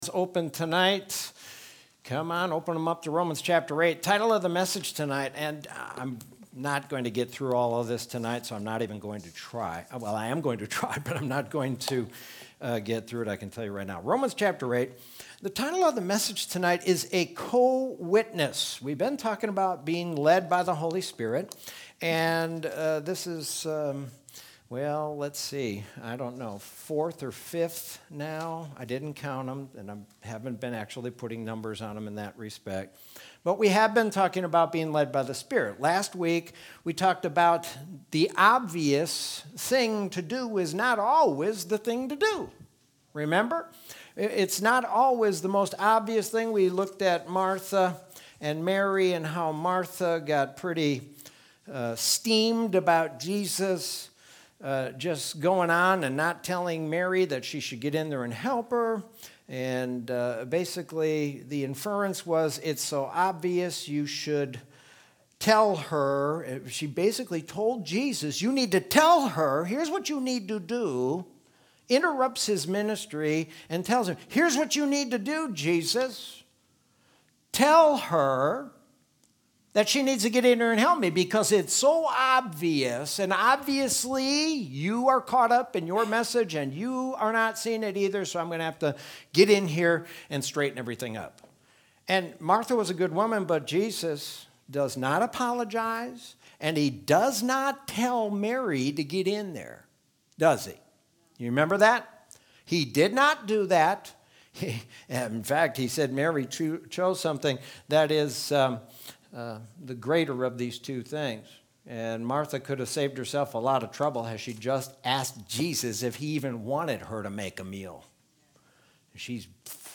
Sermon from Wednesday, October 14th, 2020.